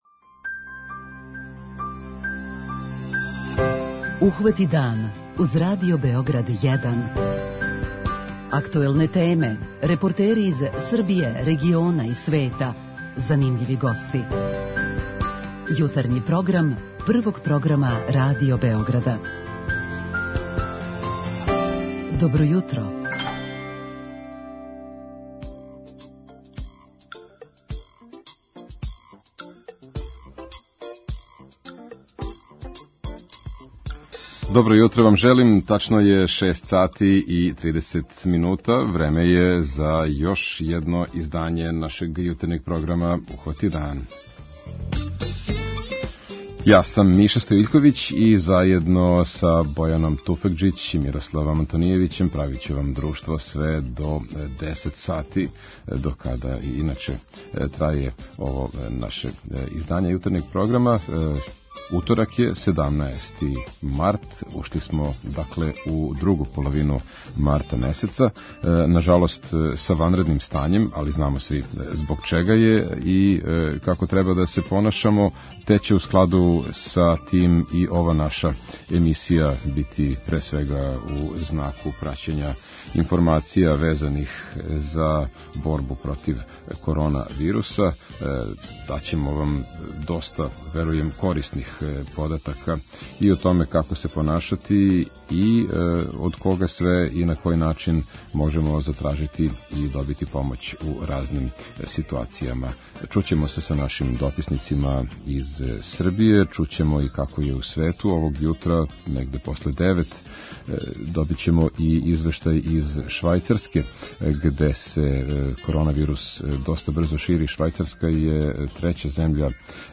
У складу с проглашеним ванредним стањем у Србији због пандемије коронавируса ЦОВИД-19, највећи део јутарњег програма биће посвећен овој теми - преносићемо све информације како нам буду стизале, укључиваћемо репортере из земље и света и потрудити се да добијемо што више података о томе како ће нам изгледати живот у условима ограниченог кретања и пословања.
Имаћемо и телефонско укључење из Швајцарске која је одмах иза Италије по броју заражених у односу на број становника.